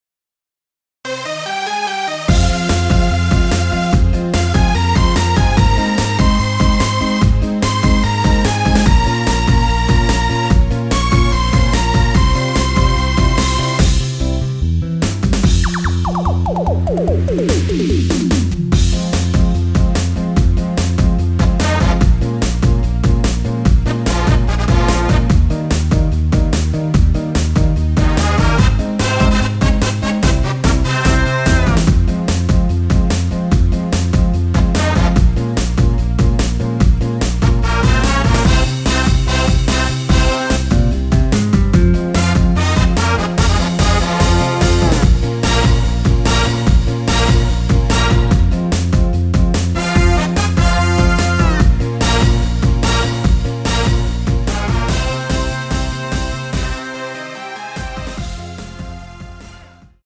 Fm
앞부분30초, 뒷부분30초씩 편집해서 올려 드리고 있습니다.
중간에 음이 끈어지고 다시 나오는 이유는